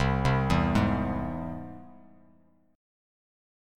Listen to C+ strummed